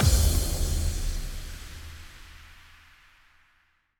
Big Drum Hit 01.wav